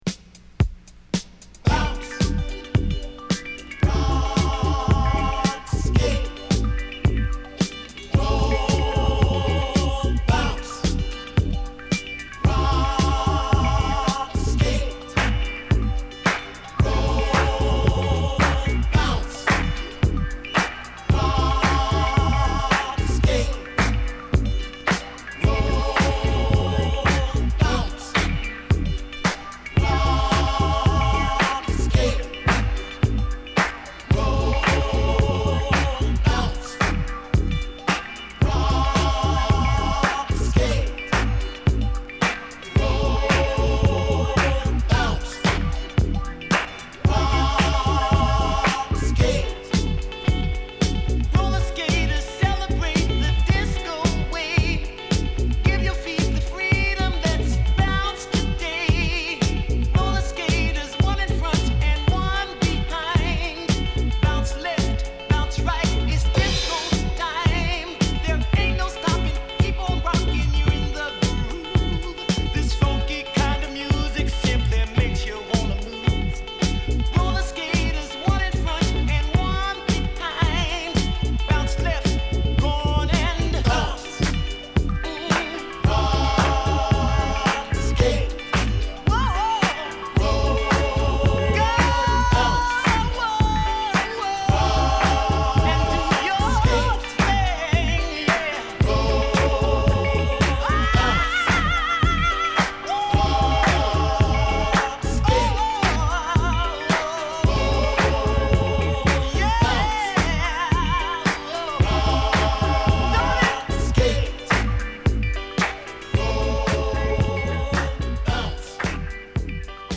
タイトなドラムに華を添えるクラップ&パーカッション、図太いMoogベースがカッコ良い彼らの代表曲！